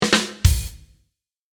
Play, download and share Comic Rim Shot 2 Sec original sound button!!!!
2-sec-drum-hit.mp3